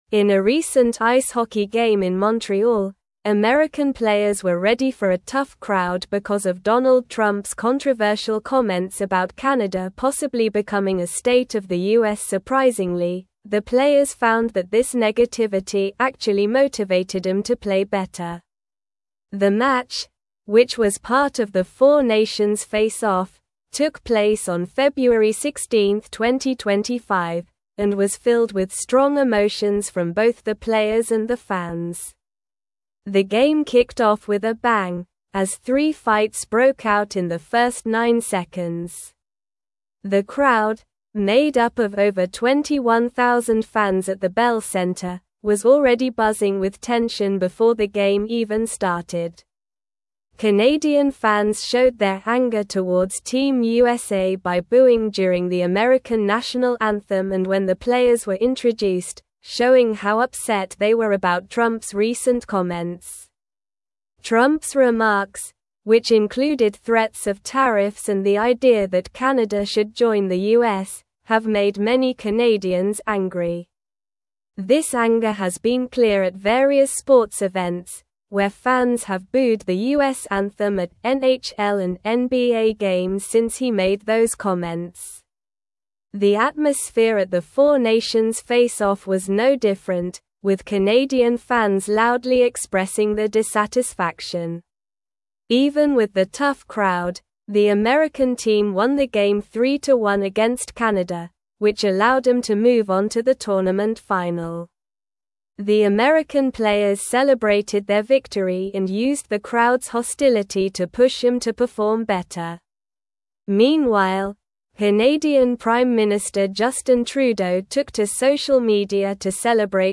Slow
English-Newsroom-Upper-Intermediate-SLOW-Reading-Intense-Rivalry-Ignites-During-4-Nations-Face-Off.mp3